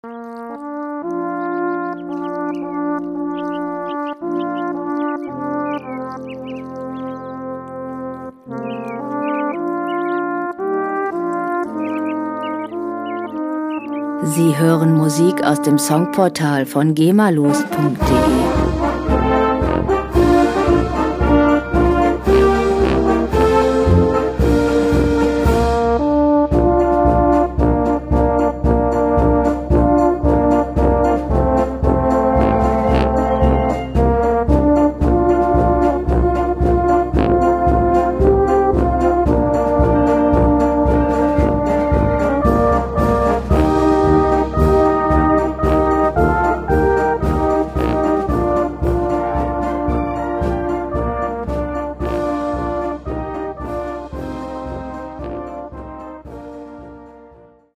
Musikstil: Volkslied
Tempo: 113 bpm
Tonart: B-Dur
Charakter: heimatlich, volkstümlich
Instrumentierung: Blaskapelle